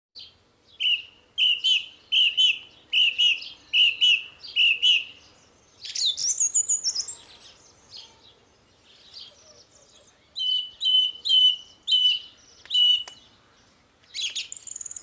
Songthrush Singing❤ If U Have Sound Effects Free Download
Upload By Sounds of birds